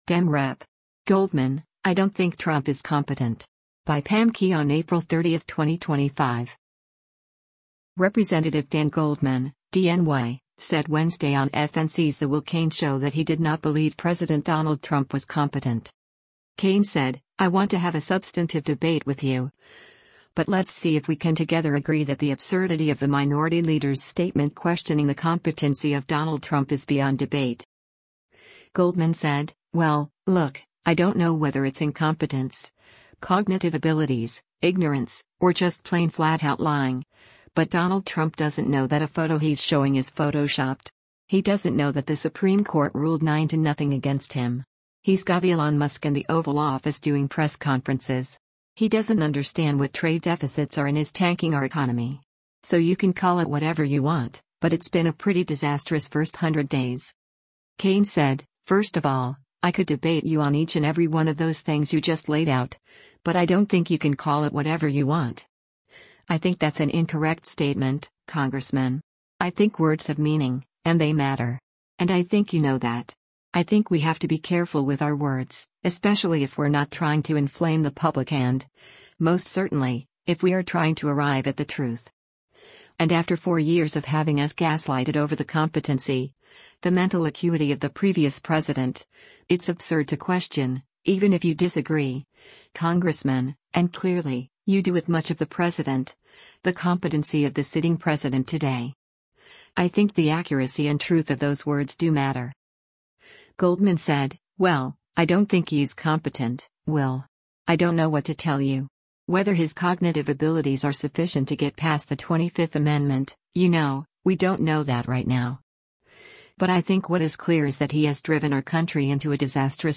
Representative Dan Goldman (D-NY) said Wednesday on FNC’s “The Will Cain Show” that he did not believe President Donald Trump was “competent.”